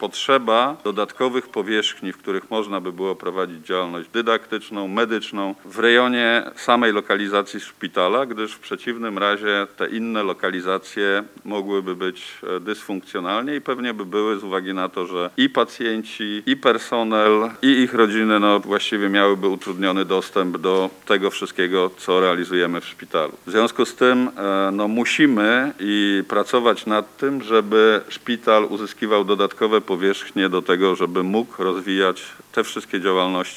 Na konferencji prasowej zarządu województwa, z powodów medycznych, zabrakło marszałek Elżbiety Polak.